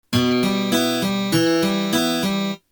Способы аккомпанимента перебором
Em (2/4)